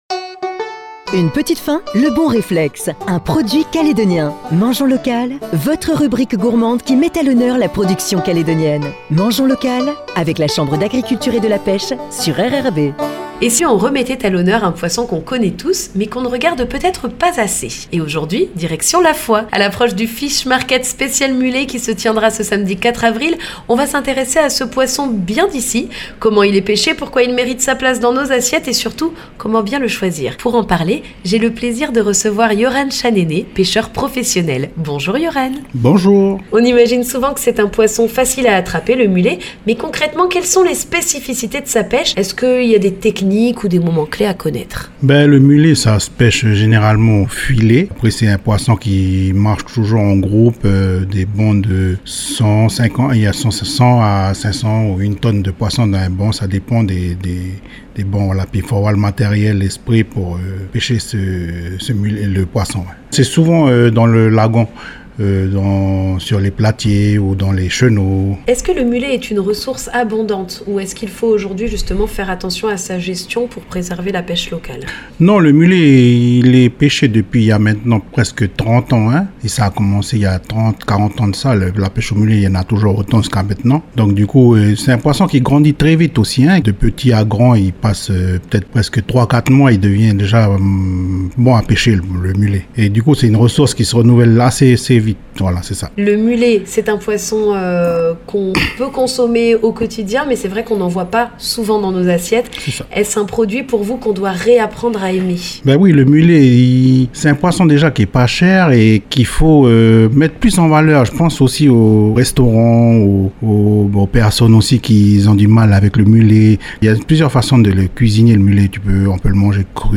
Direction La Foa, à l’occasion du Fish Market spécial mulet du 4 avril